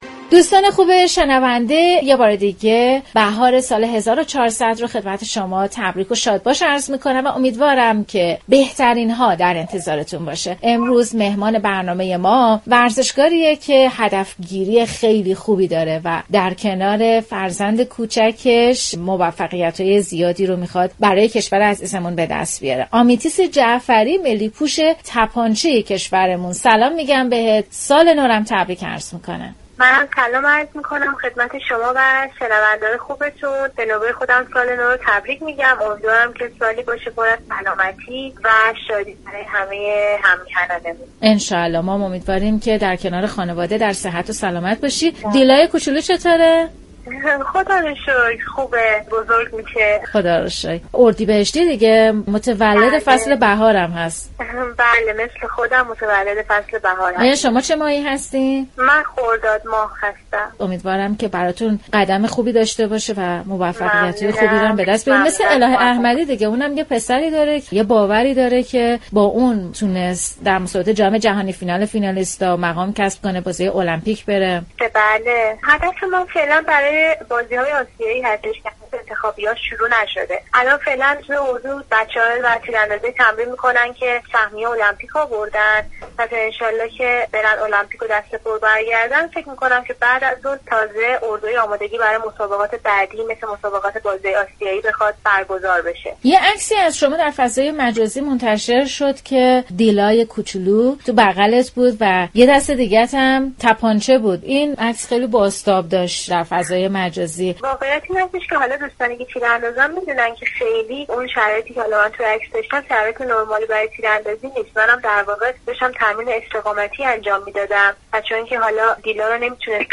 در این برنامه با بانوان قهرمان و مدال آور ایران گفتگو می شود.